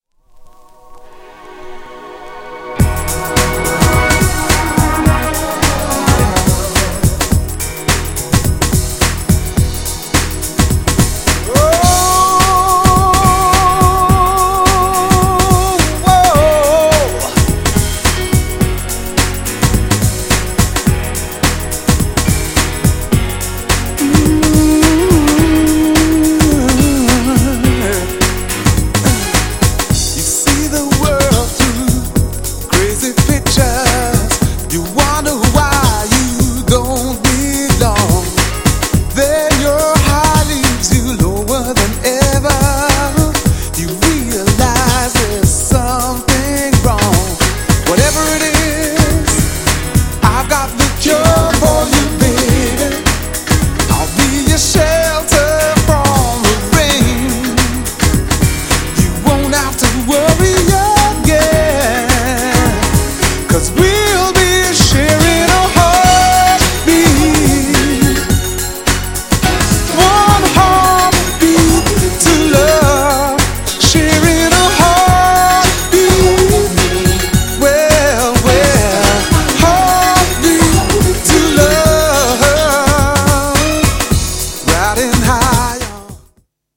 R&B MIXも良い!!
GENRE House
BPM 106〜110BPM